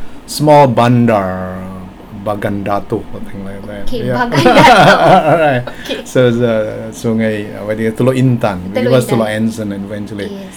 S1 = Indonesian male S2 = Malaysian female Context: S1 is discussing visiting some of his relatives in Perak state in Malaysia.
But he was about to refer to a place name starting with Sungai ('river'), and S2 was unable to hear this.